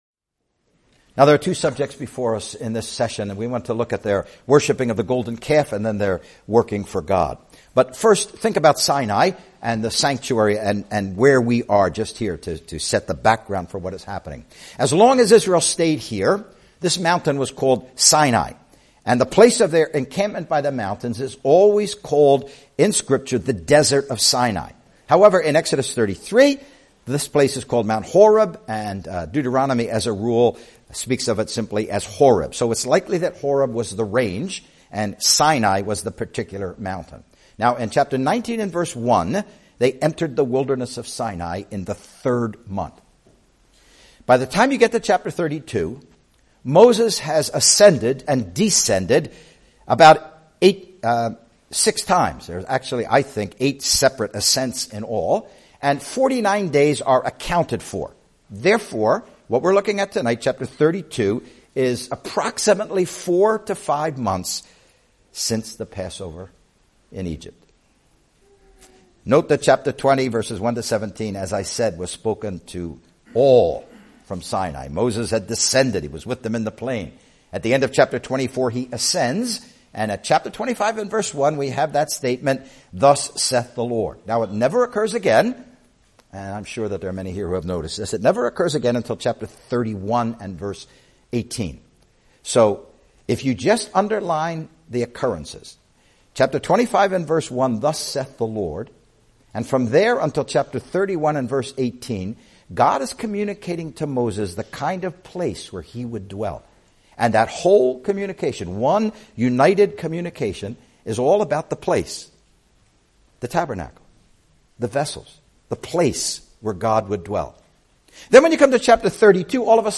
(Preached: 5th October 2019)